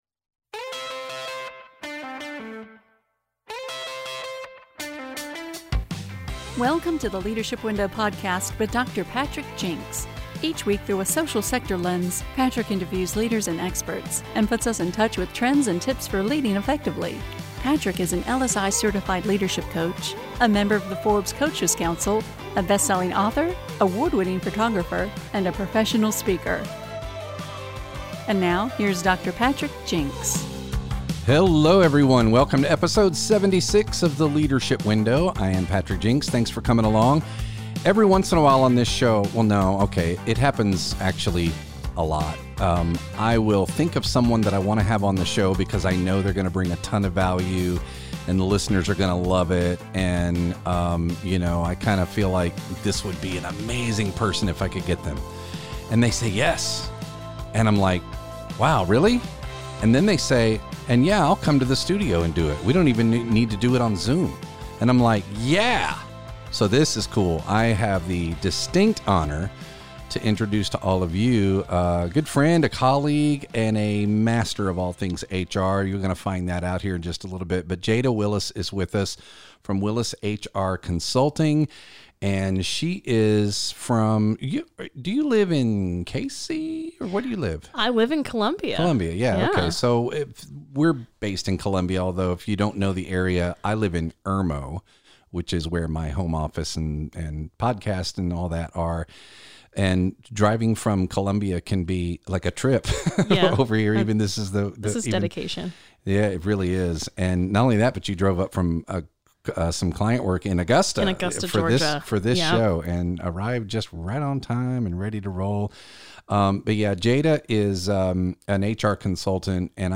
Episode 76 – A Conversation about HR in the Nonprofit Sector